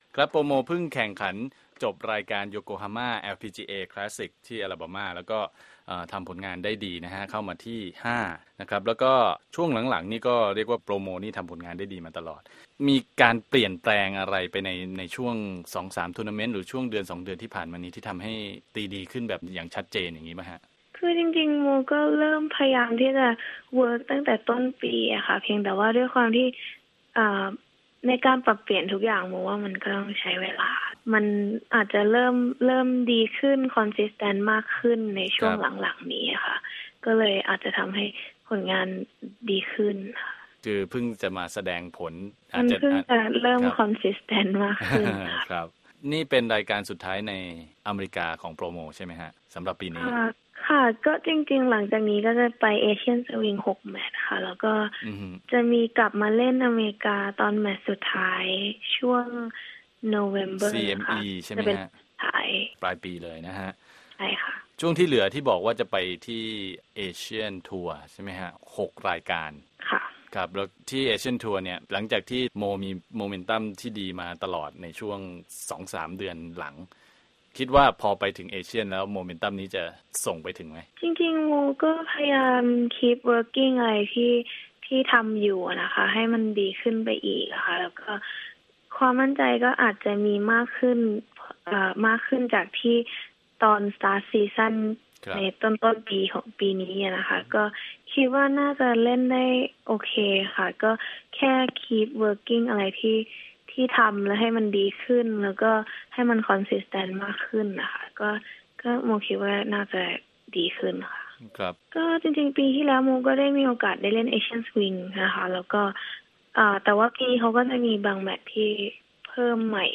LPGA Moriya Interview